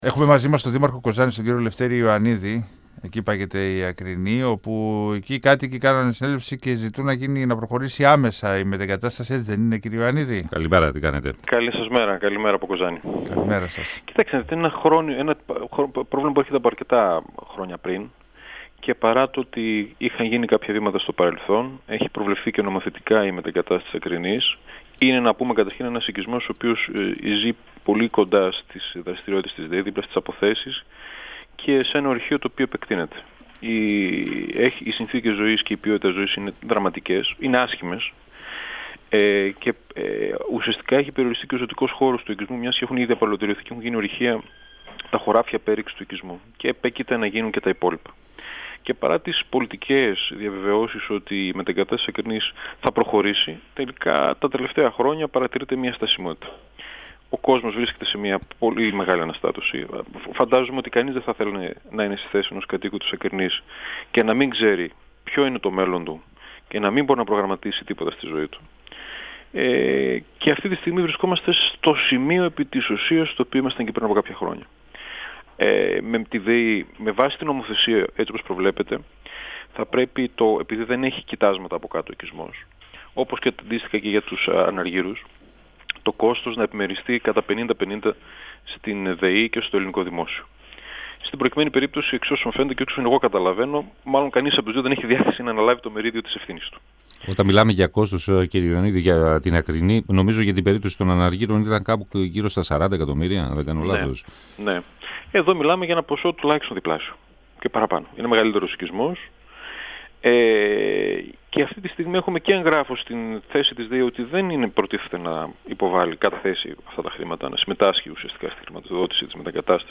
O δήμαρχος Κοζάνης Λευτέρης Ιωαννίδης, στον 102FM του Ρ.Σ.Μ. της ΕΡΤ3